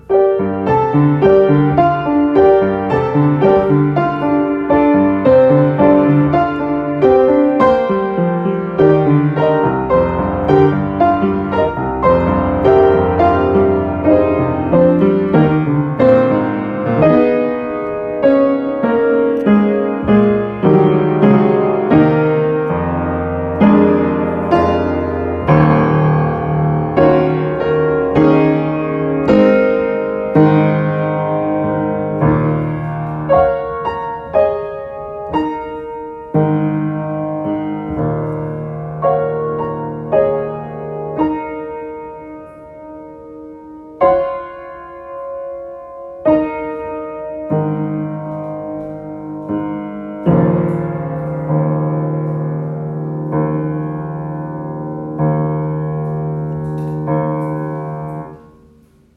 Flower+Girl+Piano+Part+3.m4a